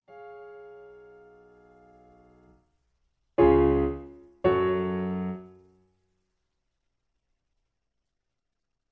Original Key. C Piano Accompaniment